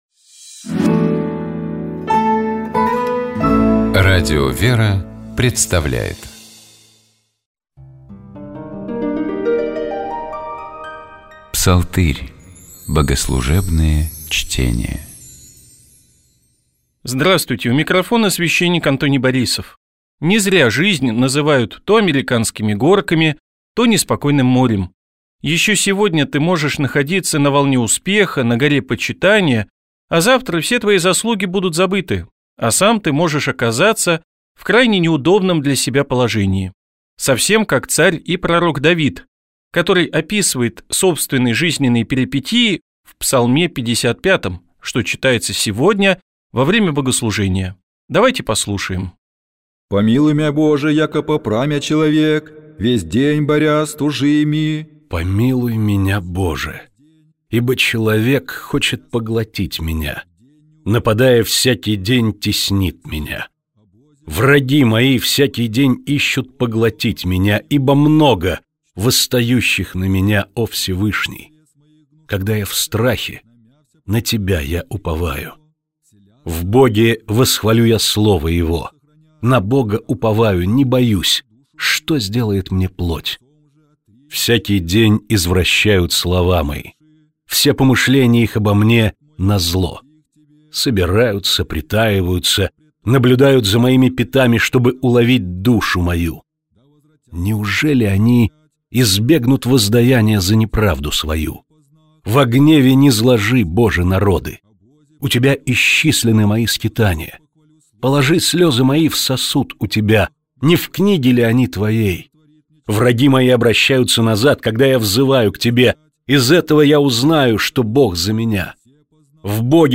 Начинается молитвословие с того, что диакон или священник запевает припев, а хор повторяет его.
Хор отвечает на возглас припевом.